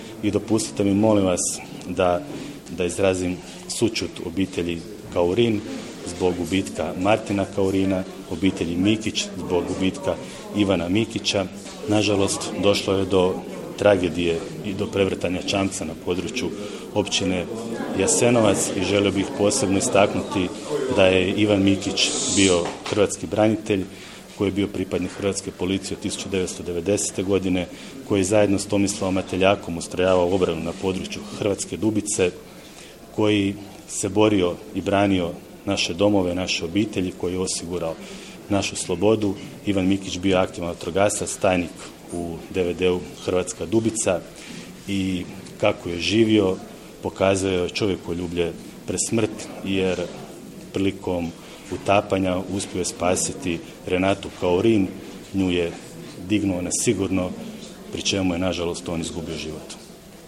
U petak, 16. prosinca 2022. godine, u prostoru DVD-a Desna Martinska Ves predstavljen je Javni poziv za dodjelu bespovratnih sredstava za obnovu objekata koje koriste javne vatrogasne postrojbe i dobrovoljna vatrogasna društva na potresom pogođenom području.